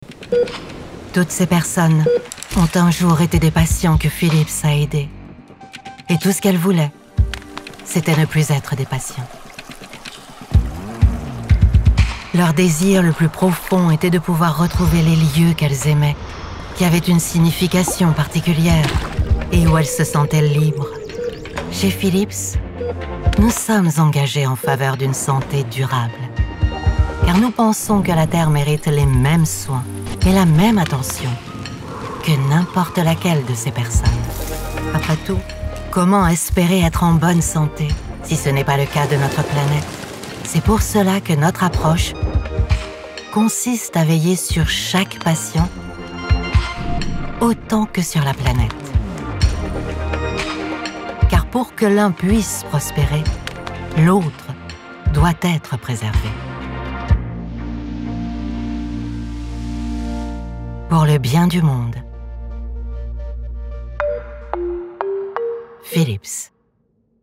Female
Approachable, Confident, Corporate, Friendly, Reassuring, Versatile, Warm
Commercial-TV-ITA Airways.mp3
Microphone: Neumann TLM 103
Audio equipment: RME Fireface UC, separate Soundproof whisper room